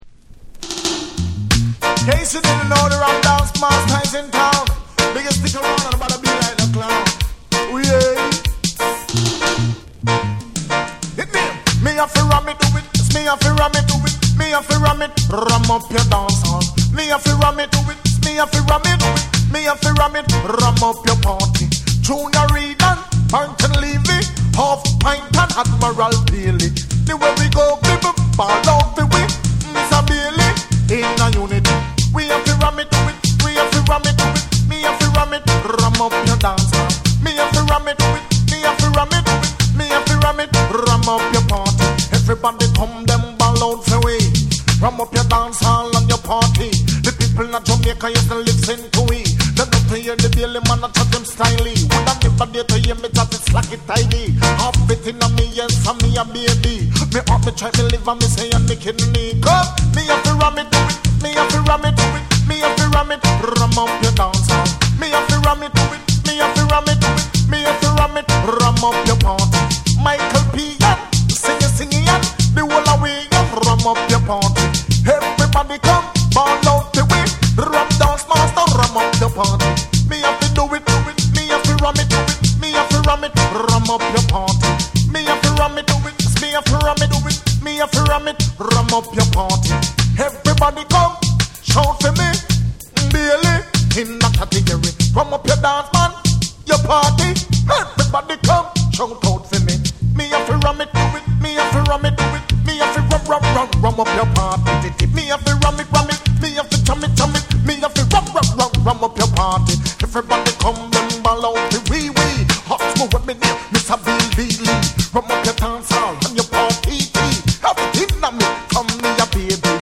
SAMPLE 1)をはじめ、骨太リディムとキレのあるトースティングが詰まった全8曲を収録。
REGGAE & DUB